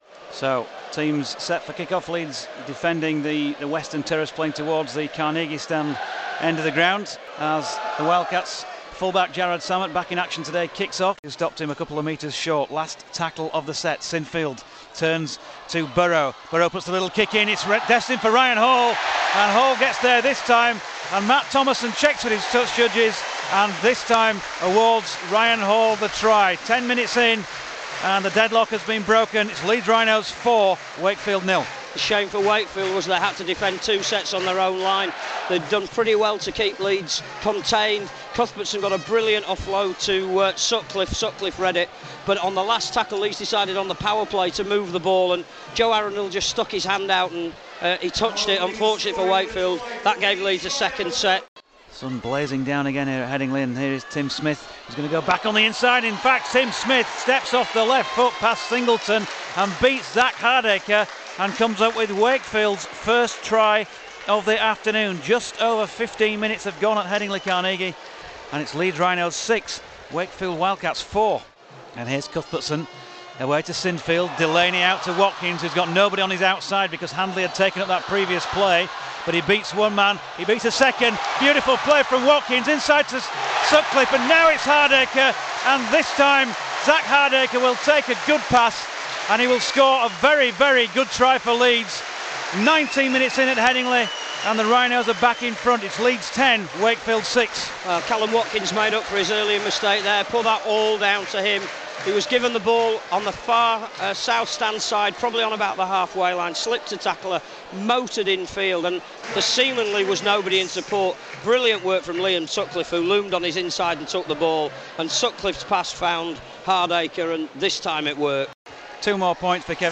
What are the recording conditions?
Highlights of the Easter Bank Holiday Monday clash between Leeds & Wakefield at Headingley in the Super League, as the league leaders march on to another victory.